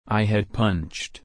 /pʌntʃt/